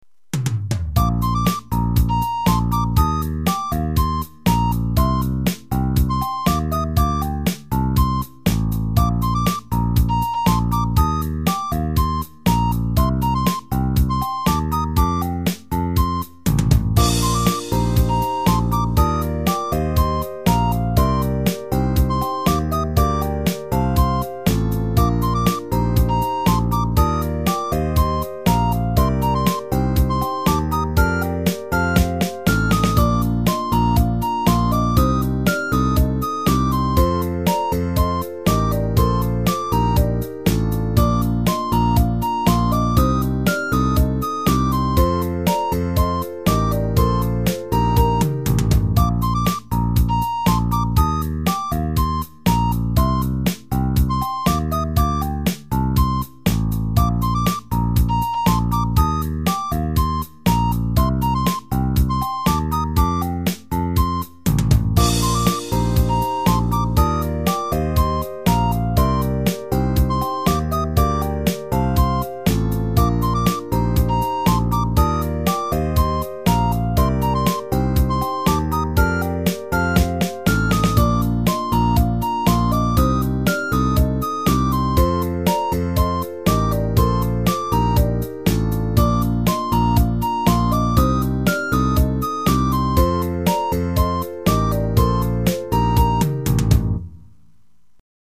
BGM：日常・平穏
コメント ほんわか休み時間、みたいな。